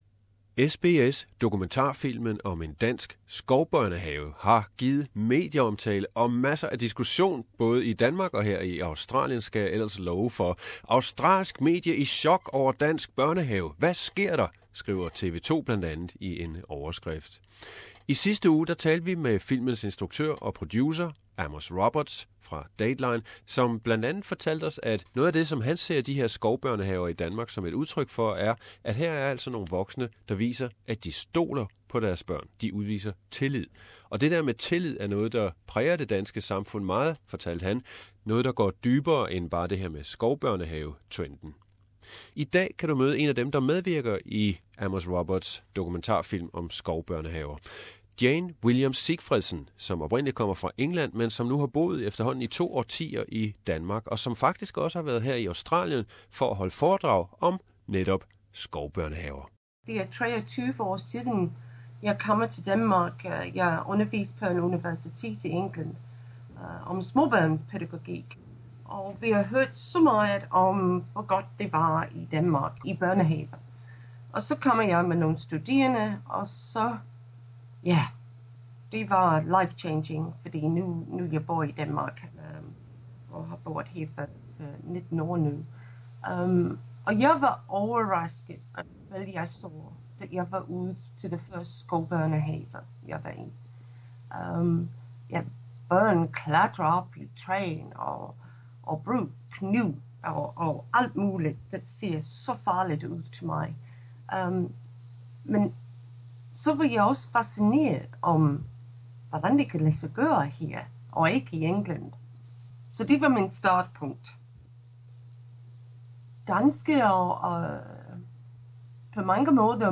The interview is in Danish language.